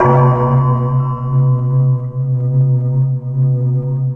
POWERBELL C3.wav